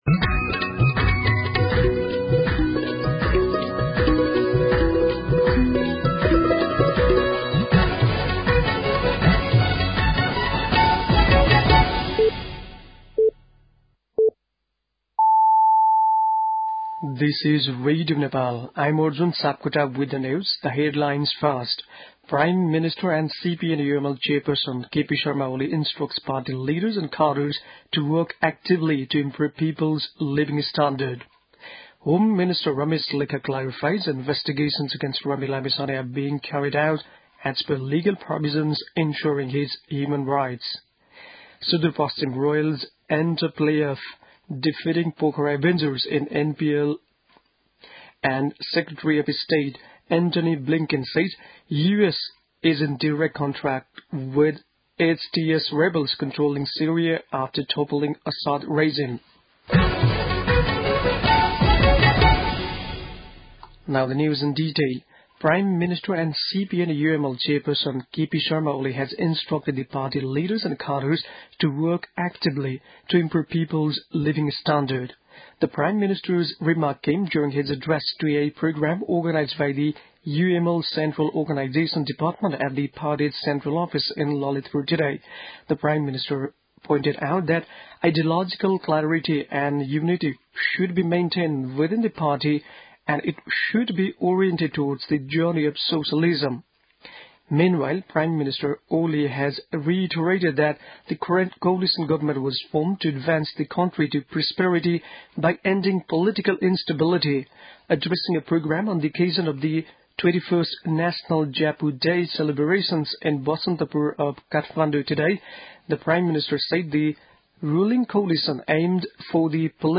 An online outlet of Nepal's national radio broadcaster
बेलुकी ८ बजेको अङ्ग्रेजी समाचार : १ पुष , २०८१
8-PM-English-News-8-30.mp3